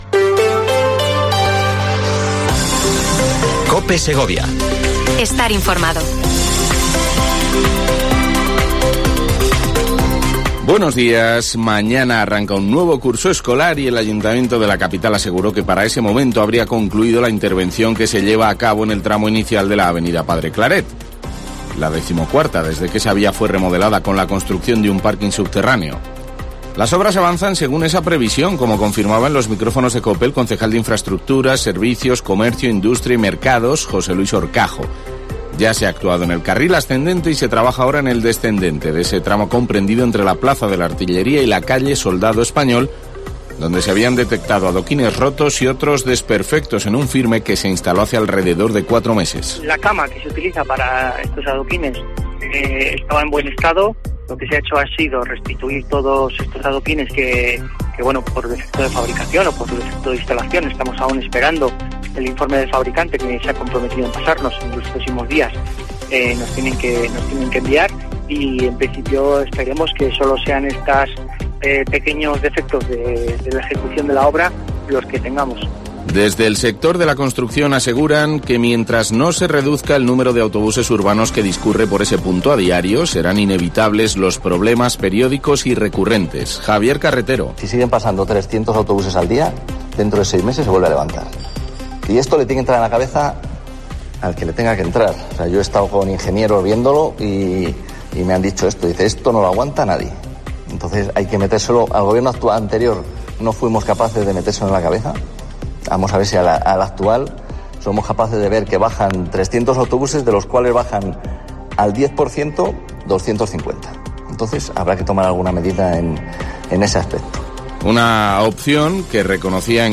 Informativo local Herrera en Cope Segovia, 8:24h. 6 septiembre.